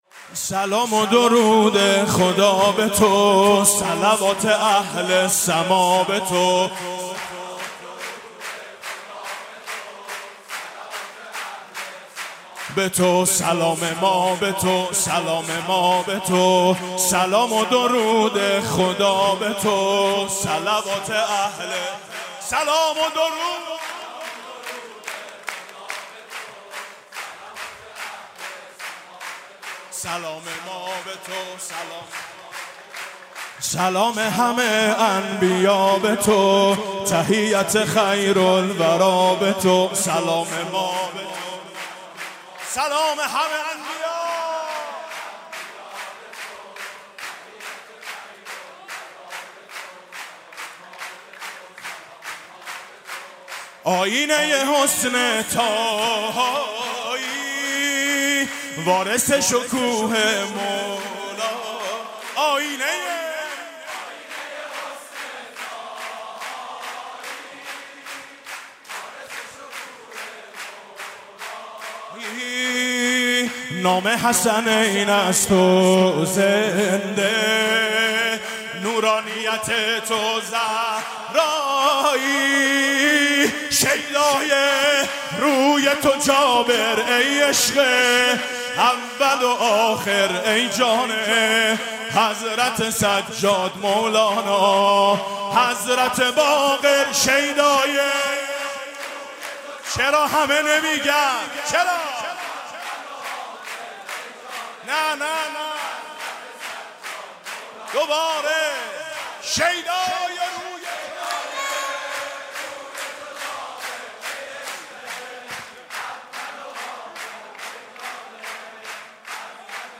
هیئت مذهبی امام علی ع مسجد جامع افضل روستای گورزانگ
مولود خوانی میلاد امام محمد باقر ع
اجرای مداحی : میلاد امام محمد باقر علیه السلام